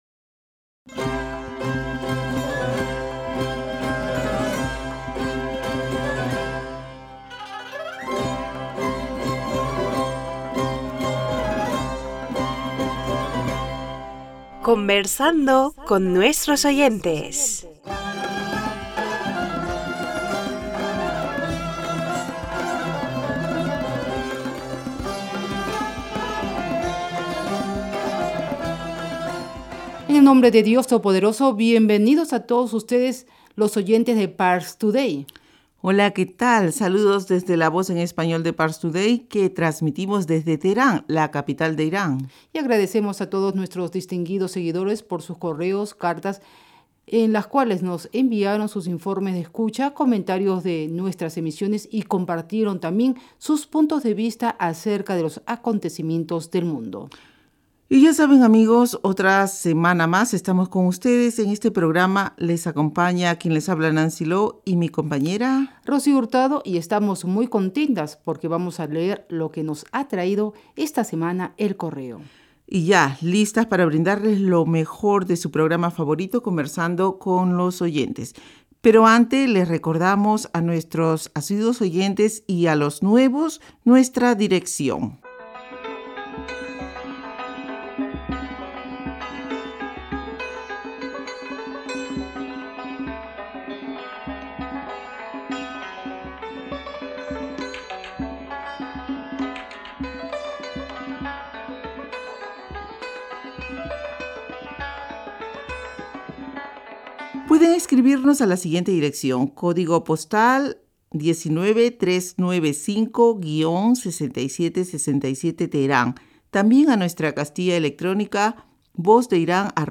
Pars Today-Las entrevistas, leer cartas y correos de los oyentes de la Voz Exterior de la R.I.I. en español.